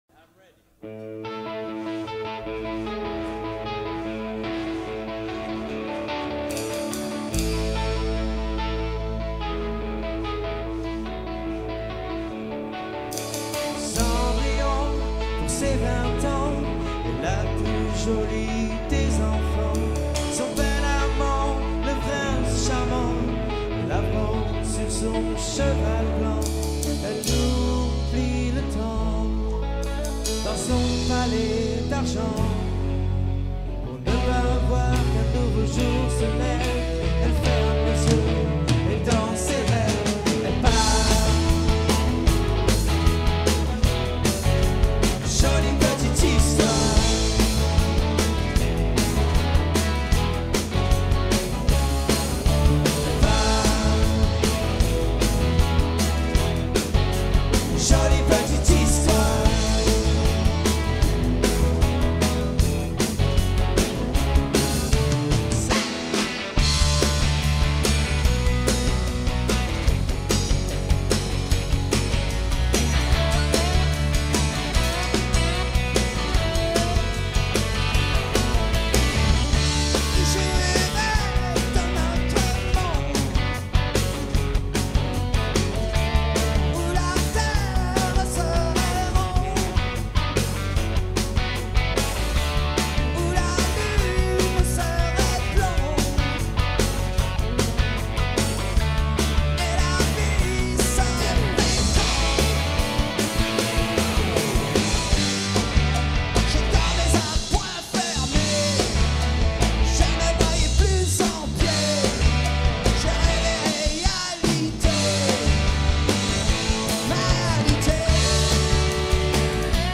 2H de Concert 100% Live
Guitare Voix Lead
Guitare Solo Chant
Basse Chœurs
Batterie Chœurs